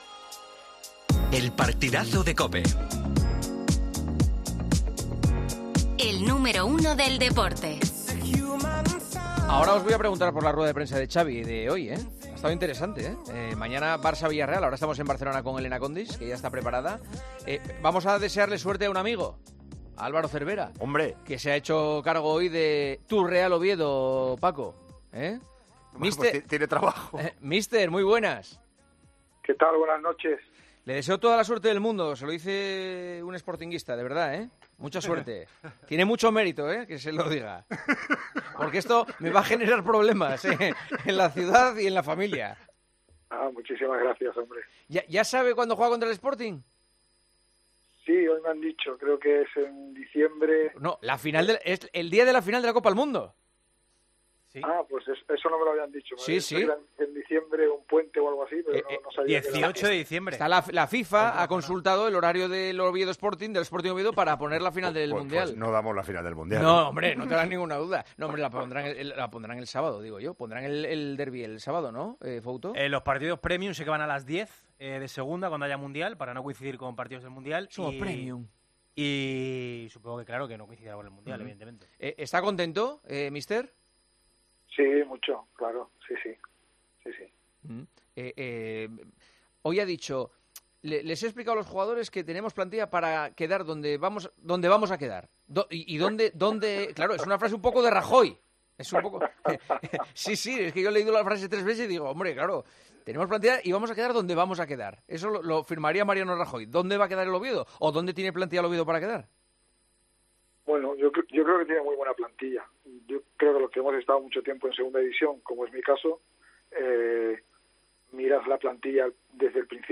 AUDIO: Hablamos con el nuevo entrenador del Oviedo sobre cómo ve las posibilidades del equipo asturiano tras la destitución de Bolo.